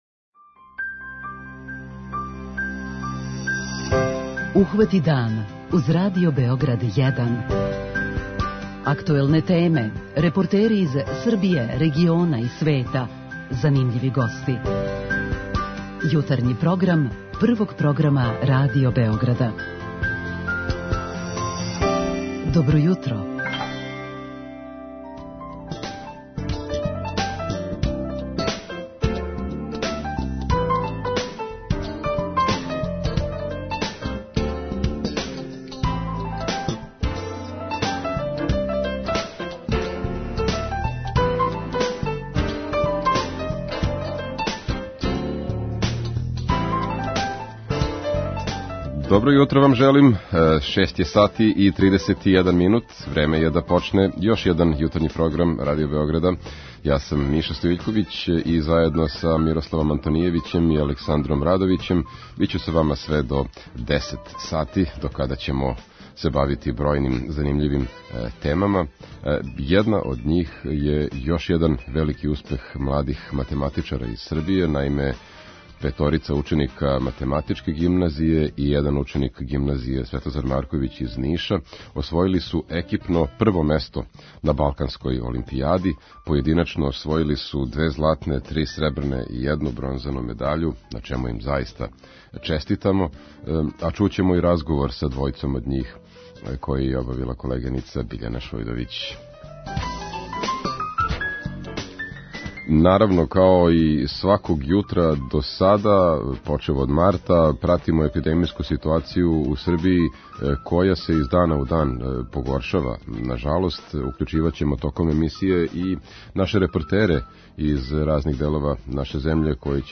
Двојицу од њих чућемо у прилогу током јутарњег дневника. преузми : 37.78 MB Ухвати дан Autor: Група аутора Јутарњи програм Радио Београда 1!
Пратимо епидемијску ситуацију у Србији која је сваког дана све тежа. Током емисије укључиваћемо дописнике из неколико градова наше земље.